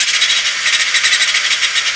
rr3-assets/files/.depot/audio/sfx/transmission_whine/kers_r18_onhigh.wav
kers_r18_onhigh.wav